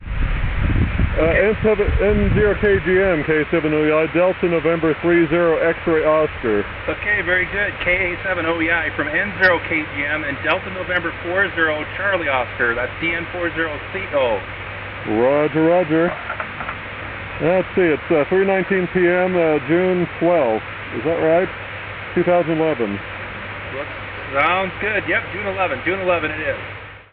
In the left channel can be heard the local, transmitted audio while the right channel contains audio from the distant end via the optical receiver.